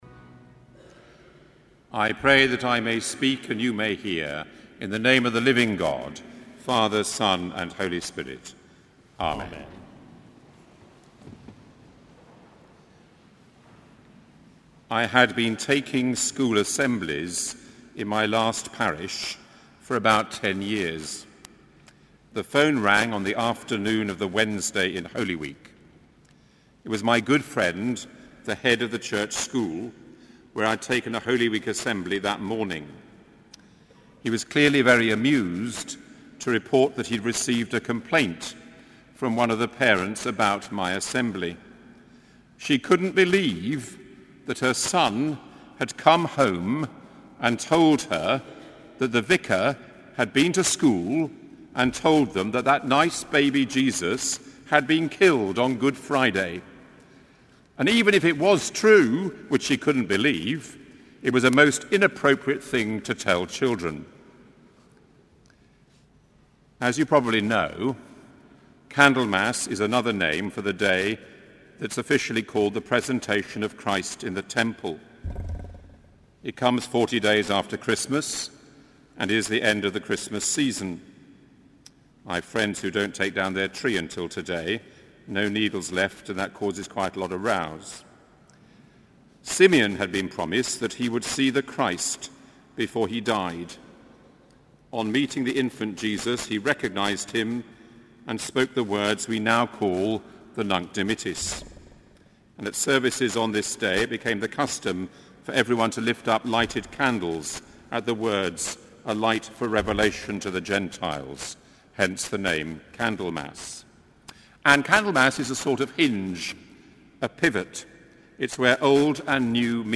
Sermon: Candlemas 2014